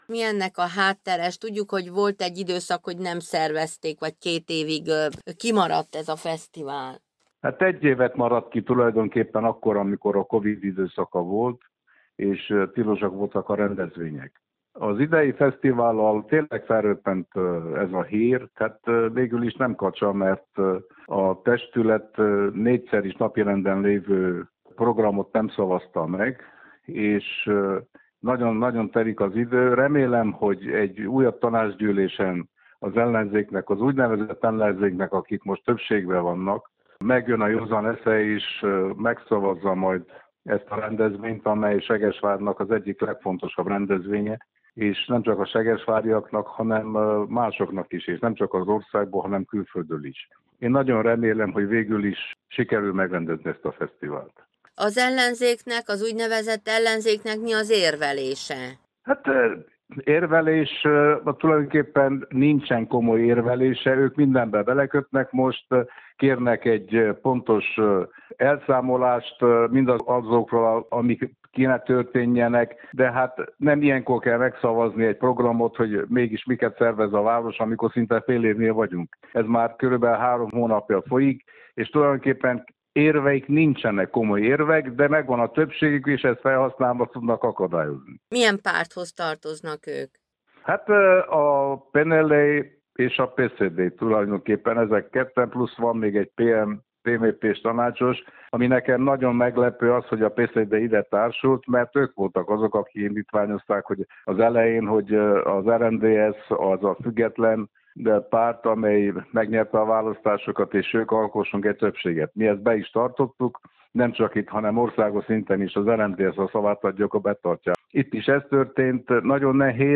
Azonban Gáll Ernő, a helyi RMDSZ vezetője, városi tanácsos úgy látja, hogy nincs minden veszve, reméli, hogy hamarosan újra napirendre tűzik a fesztivál kérdését, és az ellenzéki tanácsosok jobb belátásra térnek.